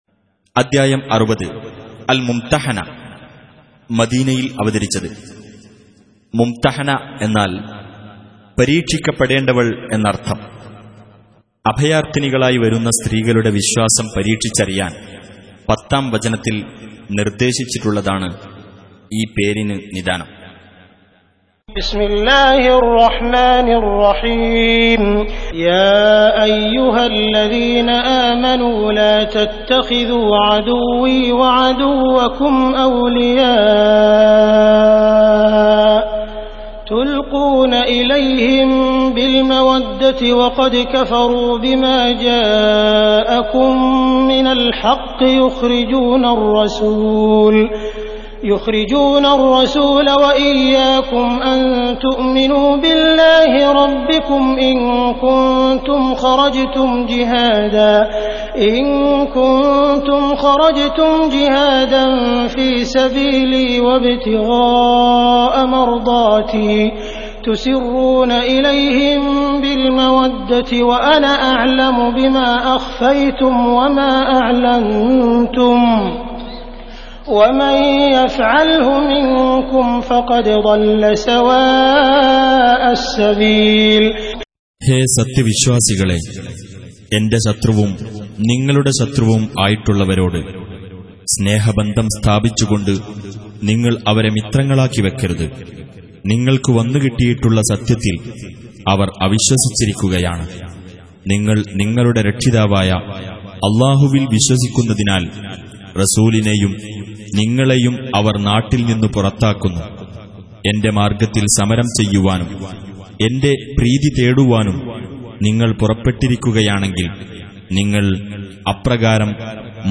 Surah Repeating تكرار السورة Download Surah حمّل السورة Reciting Mutarjamah Translation Audio for 60. Surah Al-Mumtahinah سورة الممتحنة N.B *Surah Includes Al-Basmalah Reciters Sequents تتابع التلاوات Reciters Repeats تكرار التلاوات